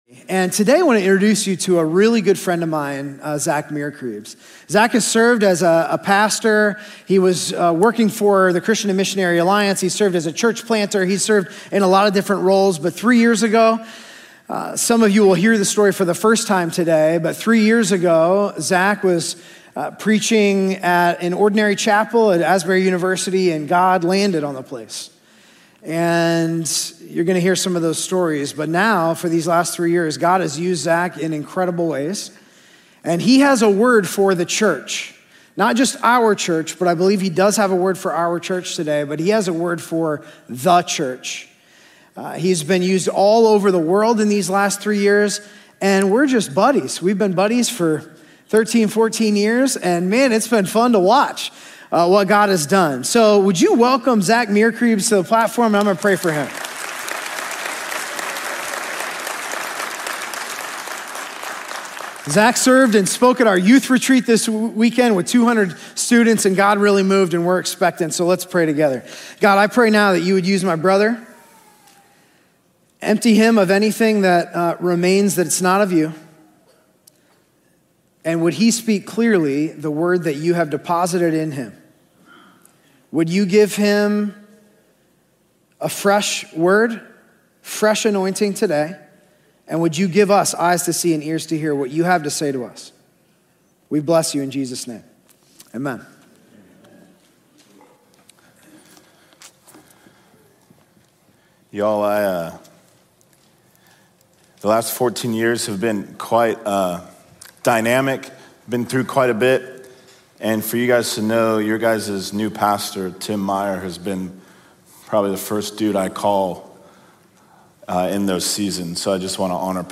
A message from Philippians 2:5–11 on humility, pride, and the posture of our hearts.